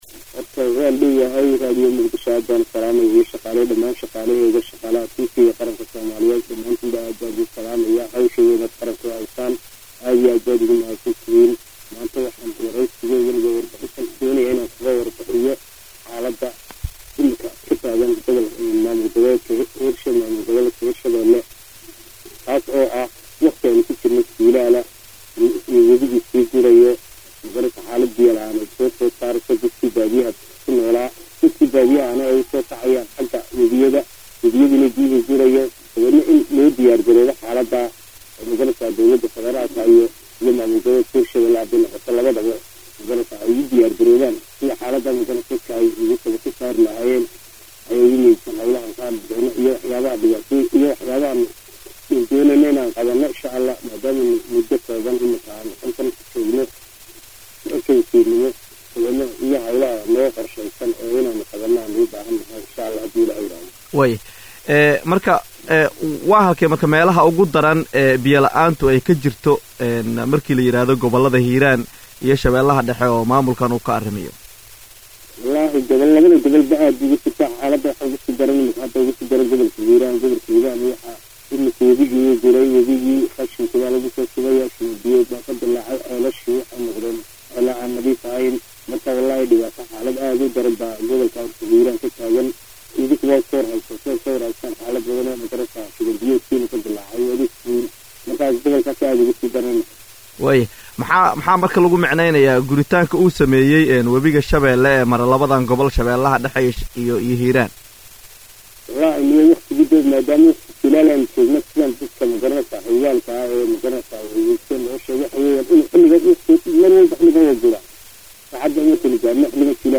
Axmed Muuse Wasiirka Tamarta iyo Biyaha Maamulka Hirshabeele wareysi uu siiyaya Radio Muqdisho ku sheegay in arrintaasi ay saamayn ku yeelatay bulshada ku nool deegaanadaasi. Related posts Degmada Waaberi oo lagu qabtay kulanka la dagaallanka maandooriyaha April 29, 2026 Taliyaha Ciidanka Xoogga Dalka oo daahfuray shirka Taliyeyaasha EAC April 29, 2026 Wasiirka ayaa tilmaamay in Biyo la,aanta ka taagan deegaanadaasi ay ka dhalatay ka dib markii webiga shabeele ee mara Gobolada Hiiraan iyo shabeelaha dhexe uu Biyo yareeyay taasi ay saamayn ku yeelatay bulshada.
waraysi-wasiirka-biyaha-iyo-tamarta-hir-shabeelle-axmed-muuse-.mp3